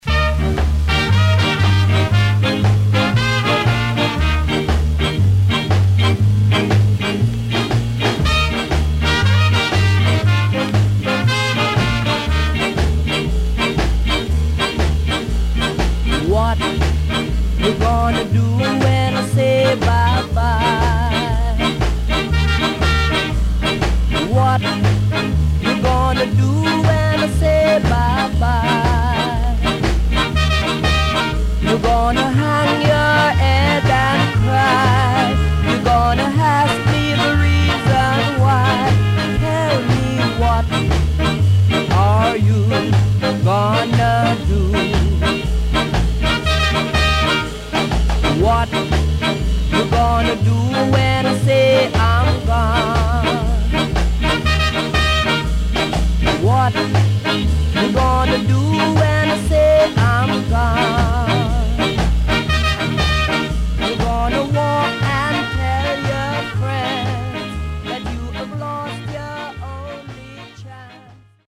SIDE B:全体にチリノイズ入ります。